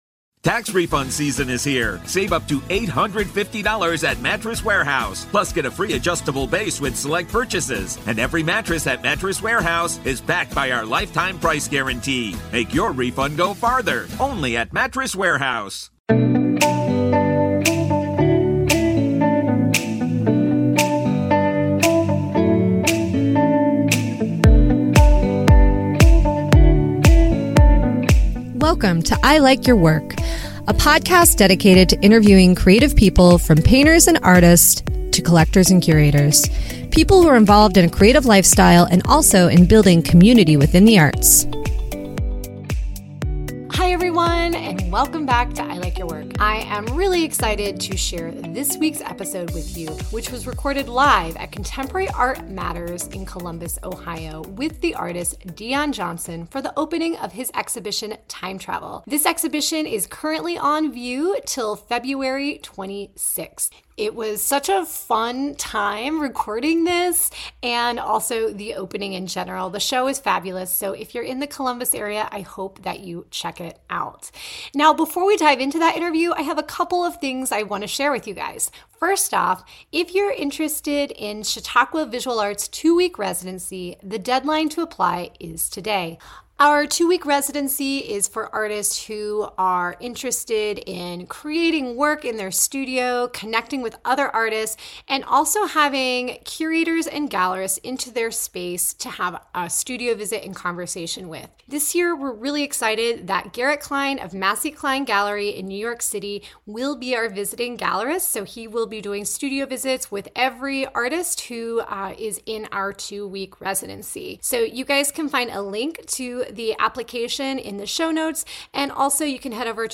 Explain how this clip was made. I Like Your Work: Conversations with Artists Live Interview